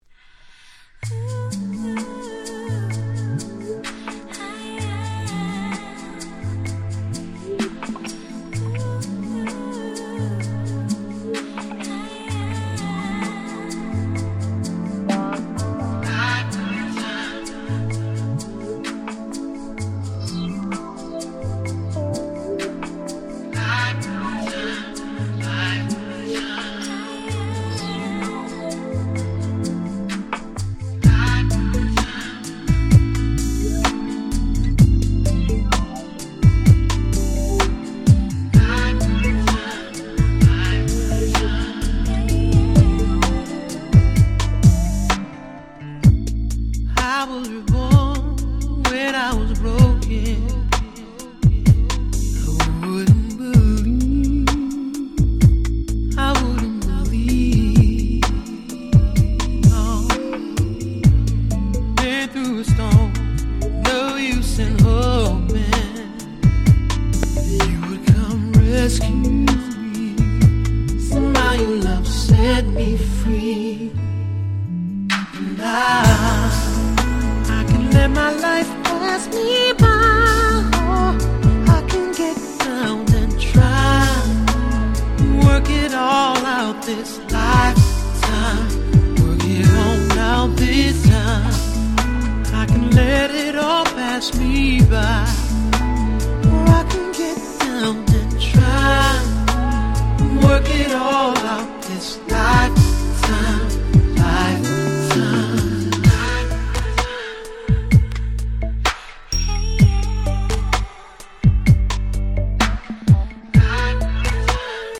01' Super Hit R&B / Neo Soul !!
ネオソウル スロウジャム